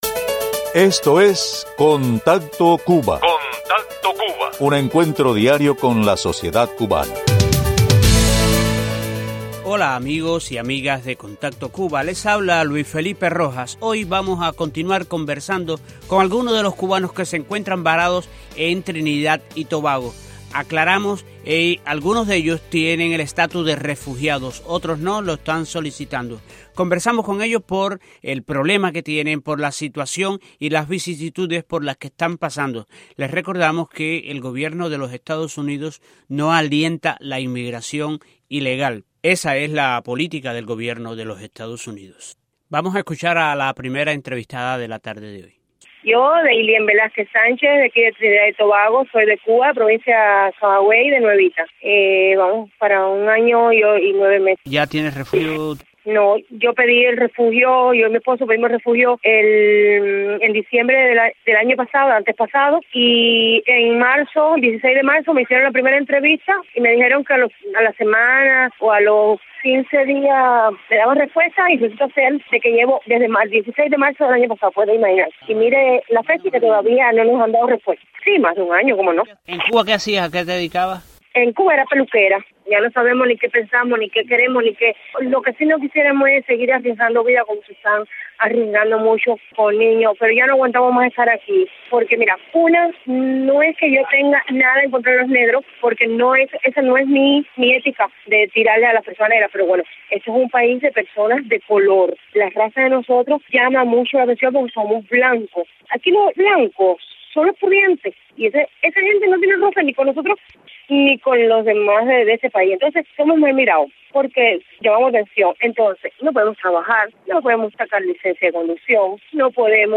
En este programa, seguimos con el tema del anterior y conversamos con cuatro cubanos más, de los que se encuentran viviendo en Trinidad y Tobago.